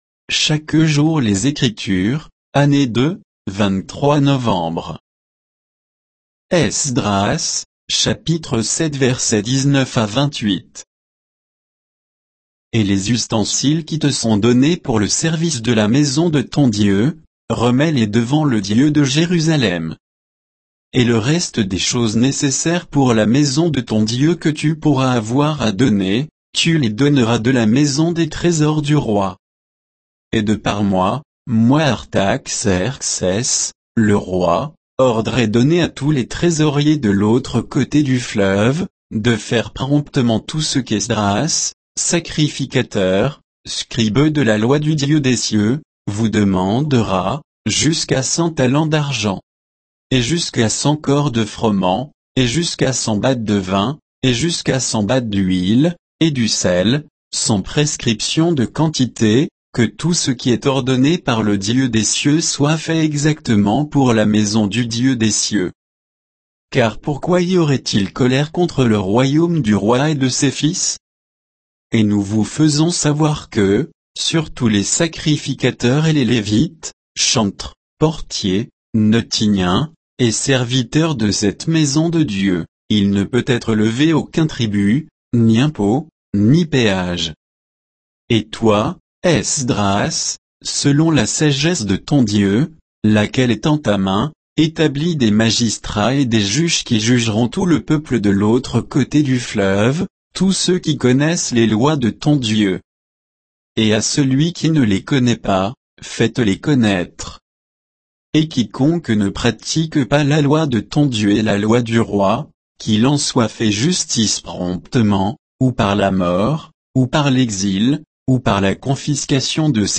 Méditation quoditienne de Chaque jour les Écritures sur Esdras 7